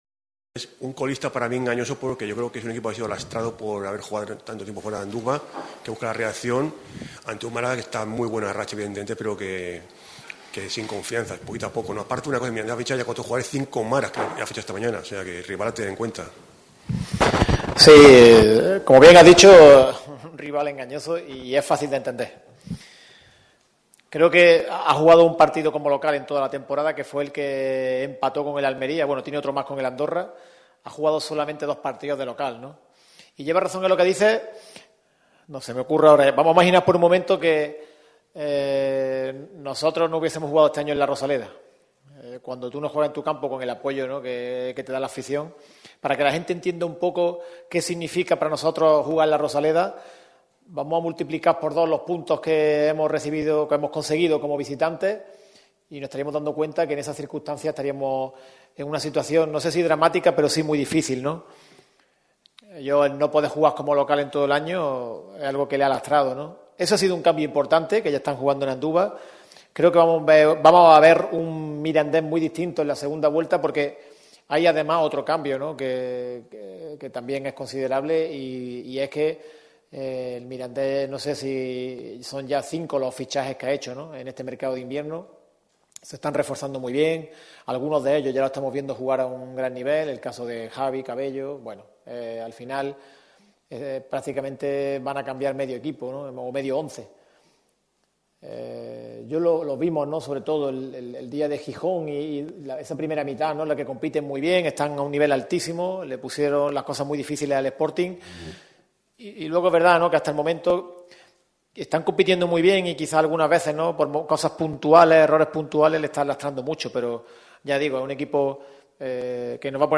Declaraciones
También ha aprovechado para transmitir la dificultad que tiene Anduva pese a que el cuadro jabato sea el farolillo rojo de la competición. Lee y escucha aquí sus declaraciones en sala de prensa.